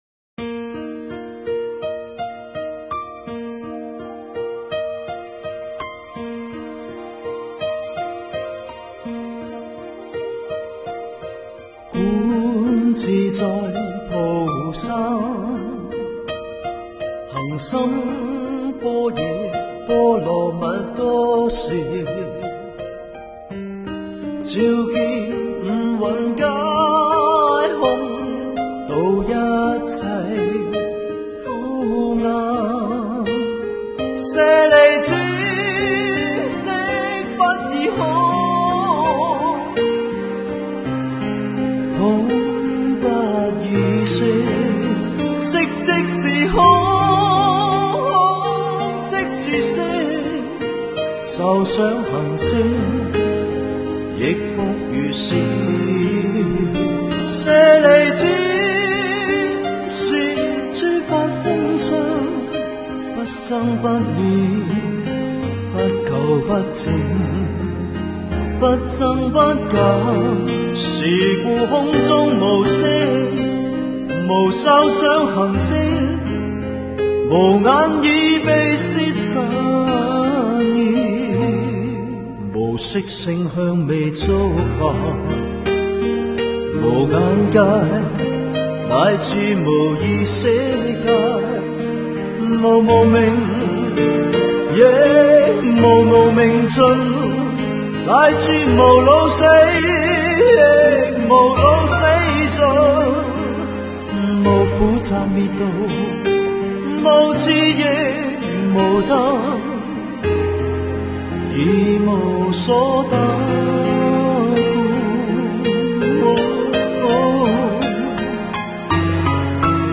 佛音 诵经 佛教音乐 返回列表 上一篇： 财宝天王心咒 下一篇： 超凡入圣 相关文章 静止世界--佛教音乐(世界禅风篇